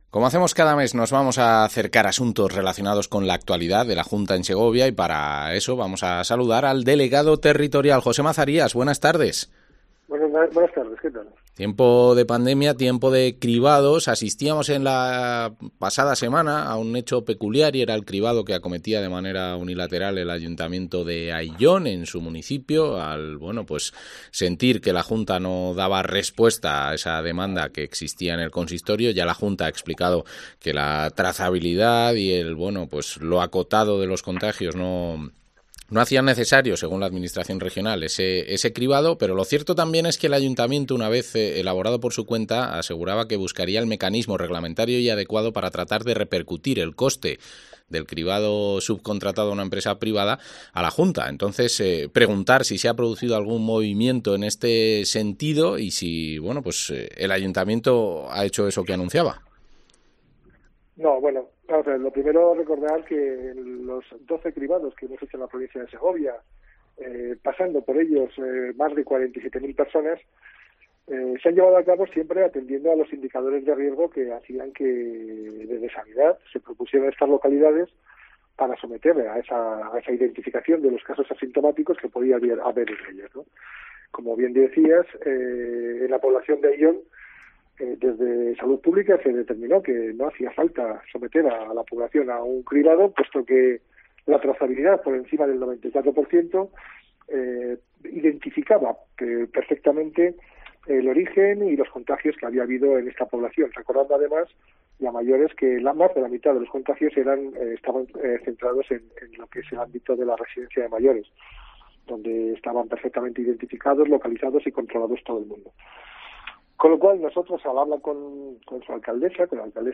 Entrevista al delegado territorial, José Mazarías